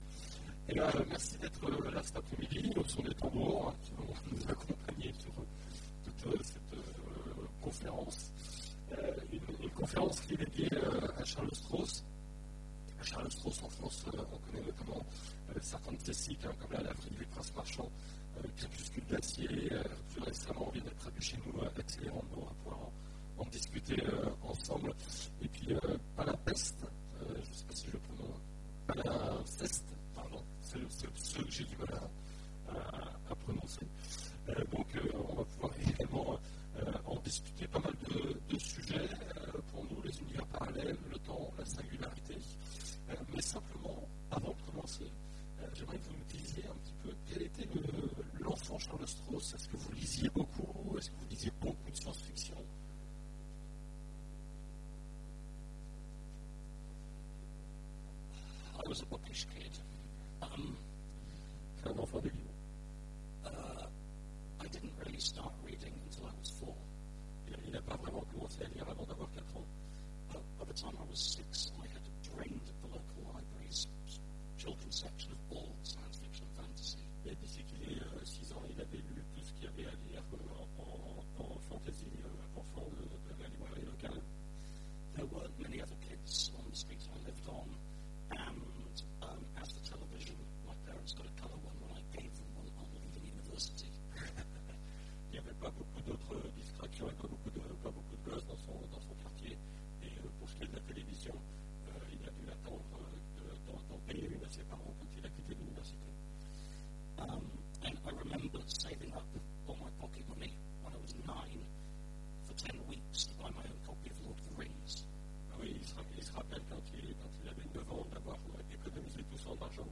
Imaginales 2015 : Entretien avec Charles Stross
- le 31/10/2017 Partager Commenter Imaginales 2015 : Entretien avec Charles Stross Télécharger le MP3 à lire aussi Charles Stross Genres / Mots-clés Rencontre avec un auteur Conférence Partager cet article